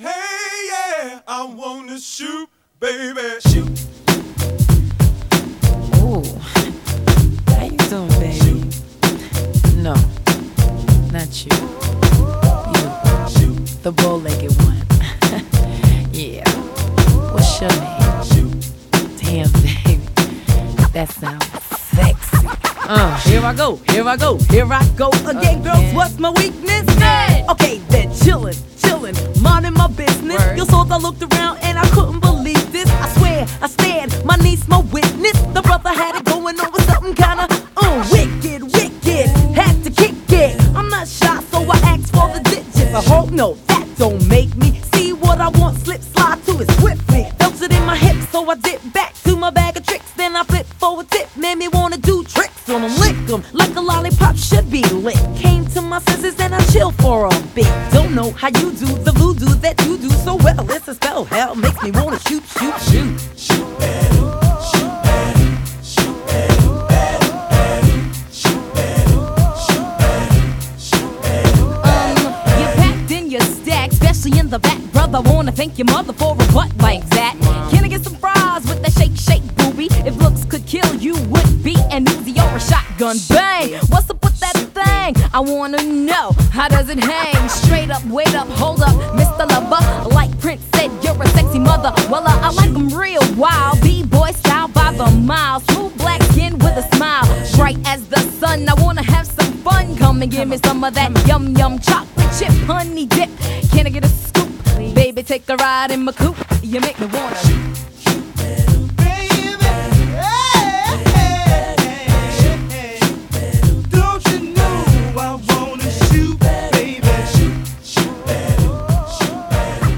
BPM97
MP3 QualityMusic Cut